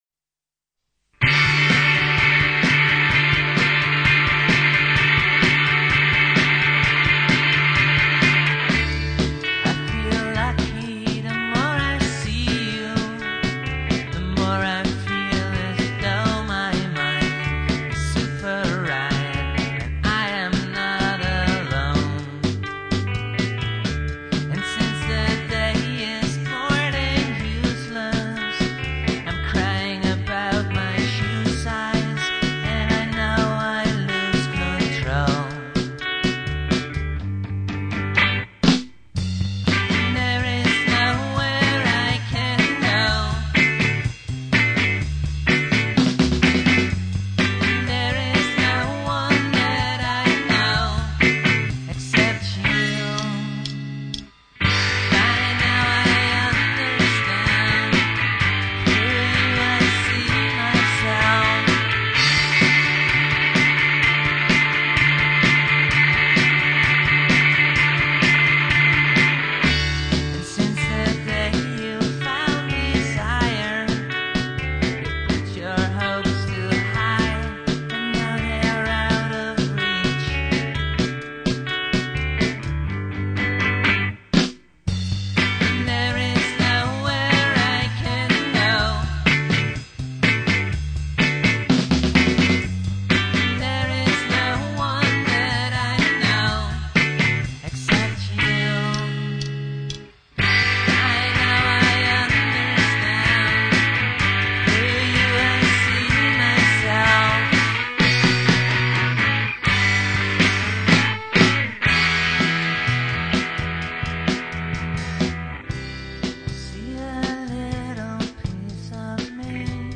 where: recorded at CMA (Amsterdam)